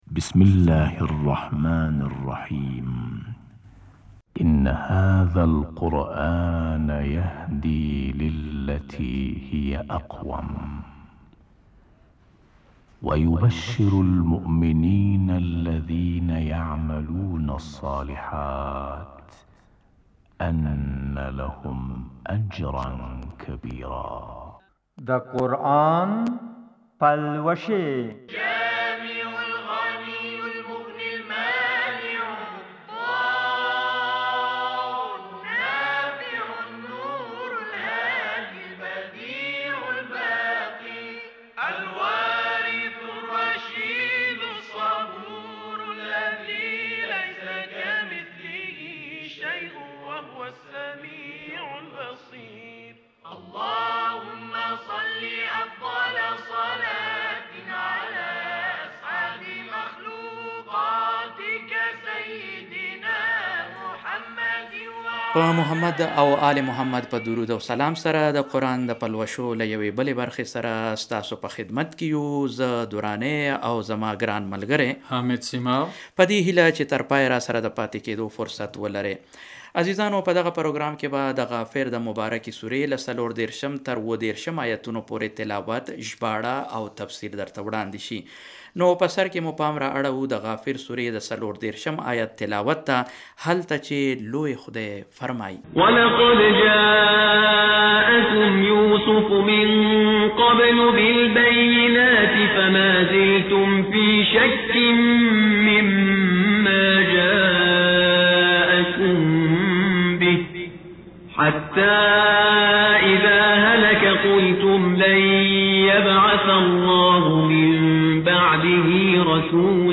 په دغه پروګرام کې به د غافر د مبارکې سورې له څلور دېرشم تر اوه دېرشم ایتونو پورې تلاوت ژباړه او تفسیر درته وړاندې شي.